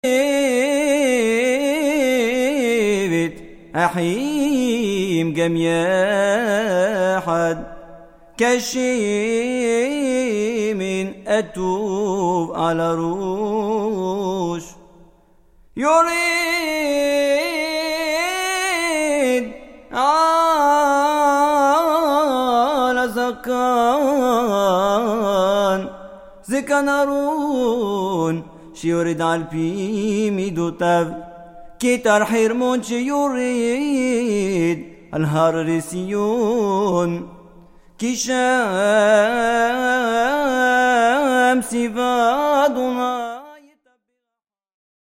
Psaumes chantés dans la tradition constantinoise